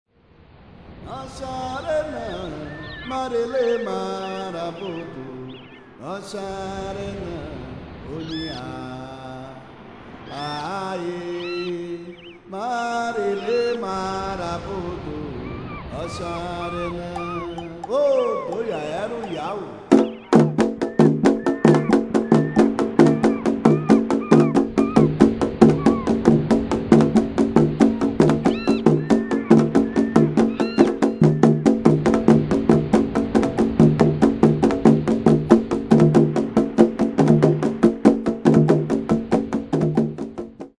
Traditional Orixa Songs and rhythms